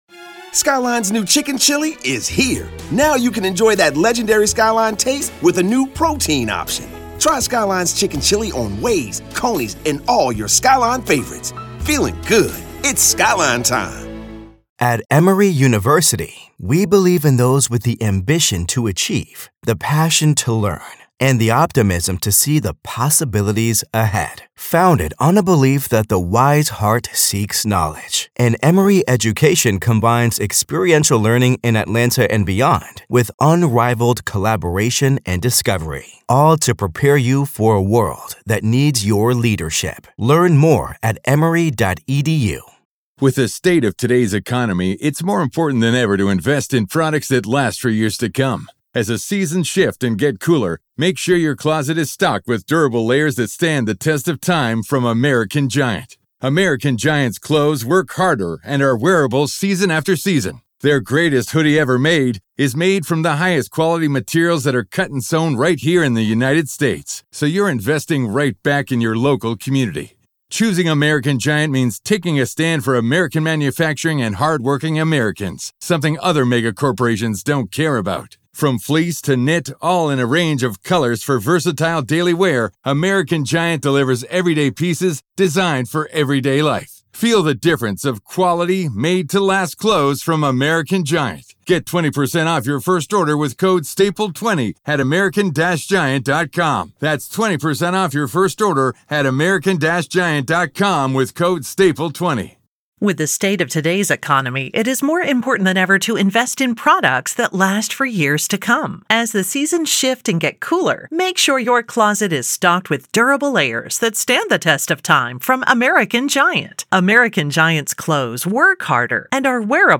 In this explosive interview